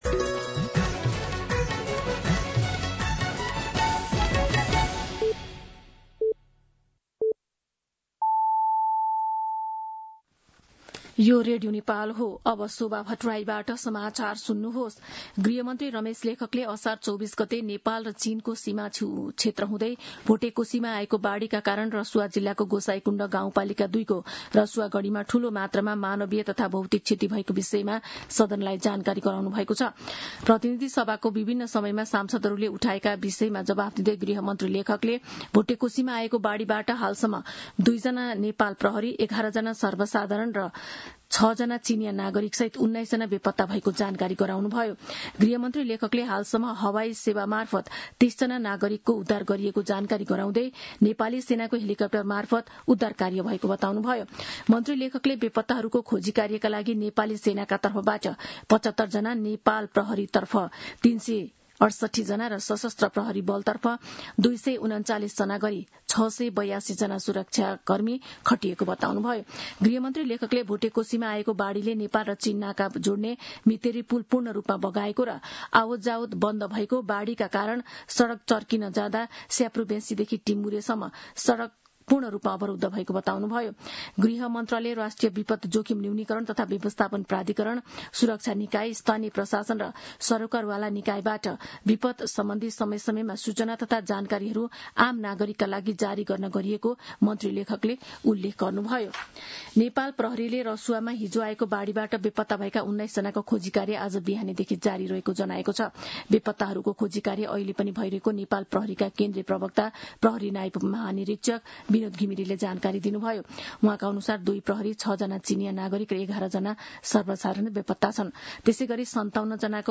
दिउँसो ४ बजेको नेपाली समाचार : २५ असार , २०८२
4-pm-News-3-25.mp3